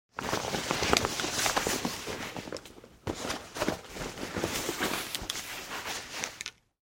Звуки портфеля